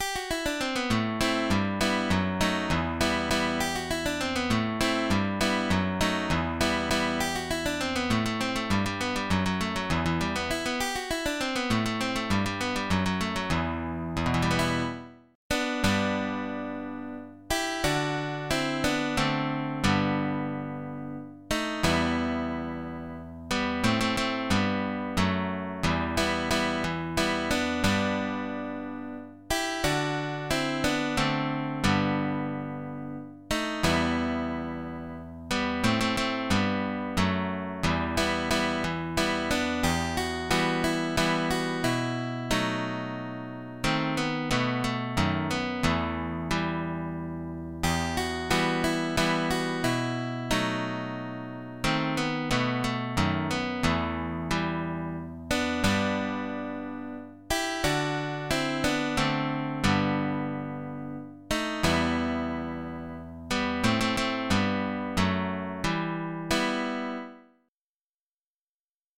Estilo criollo[tab
L’estilo è un ritmo veloce all’inizio e lento nel canto.